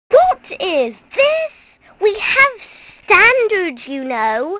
1 channel
snd_1128_icab-sad.wav